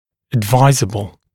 [əd’vaɪzəbl][эд’вайзэбл]рекомендуемый, целесообразный